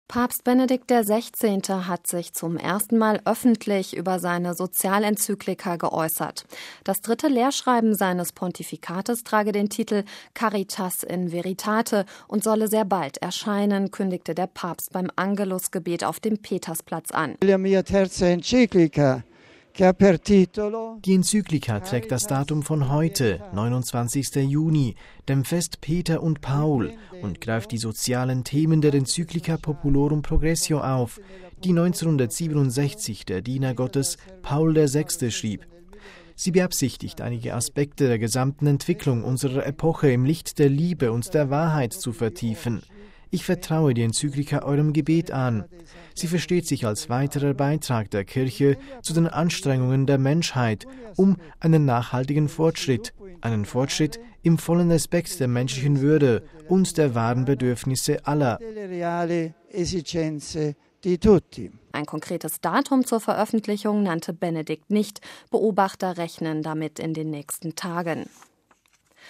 Das dritte Lehrschreiben seines Pontifikates trage den Titel „Caritas in veritate“ und solle „sehr bald“ erscheinen, kündigte der Papst beim Angelusgebet auf dem Petersplatz an.